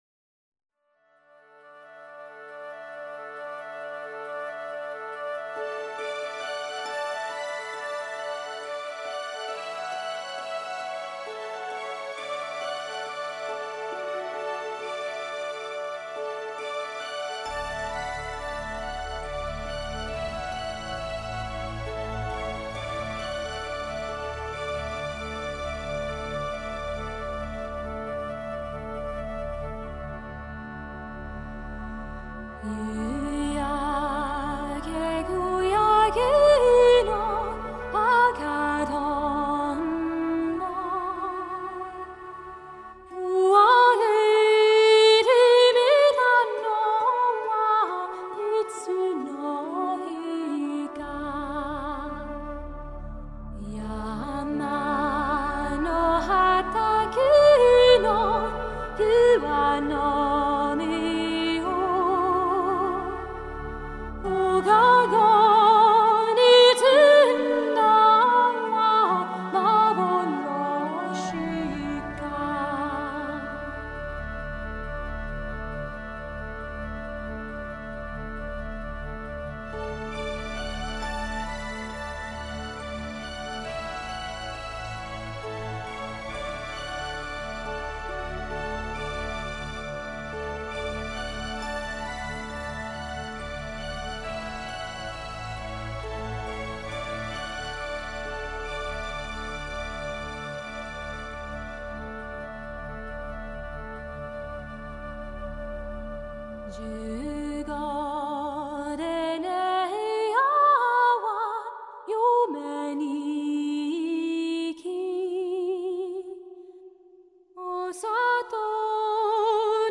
类别：古典音乐
她的演唱会同样也是从那首＜让我远离伤痛（Lascia ch’io pianga）＞开始，恬静的美声令人印象深刻。